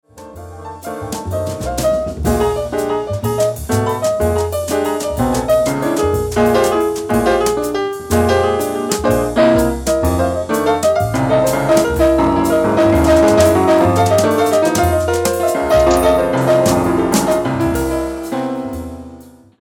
piano, stretching out on a slow blues.